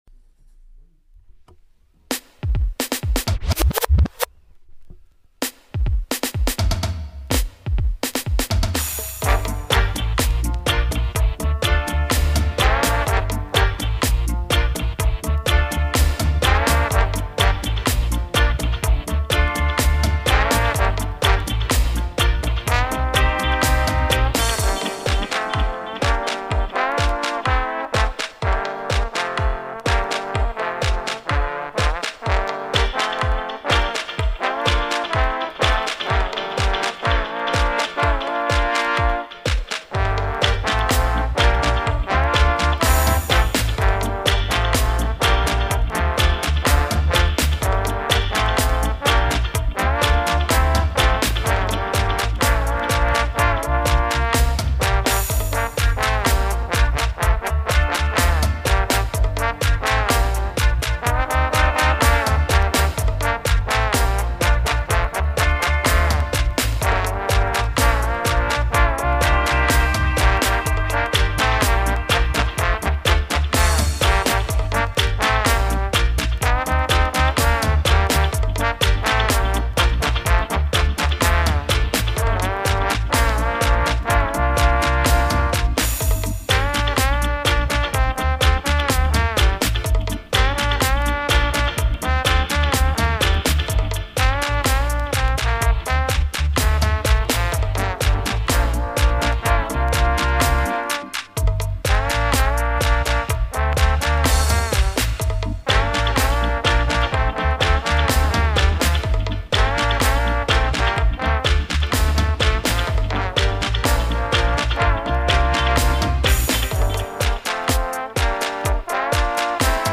1H37 of conscious roots music